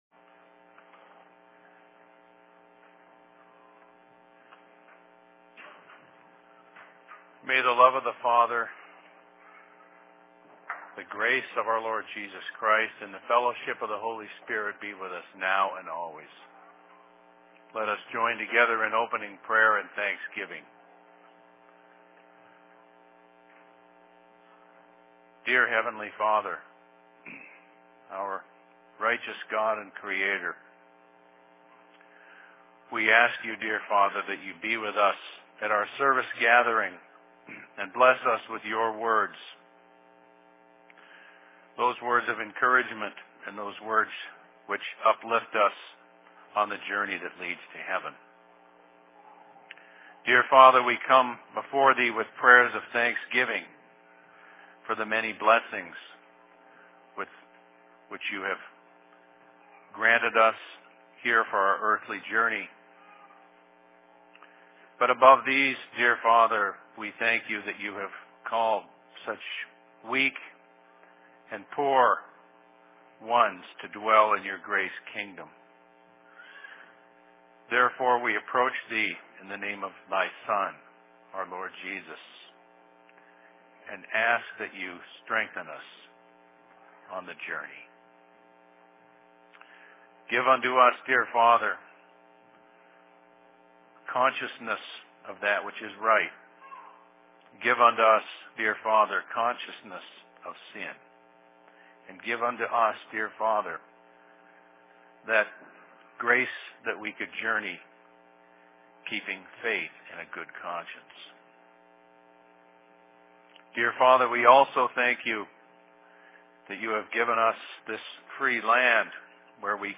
Sermon in Seattle 09.10.2011
Location: LLC Seattle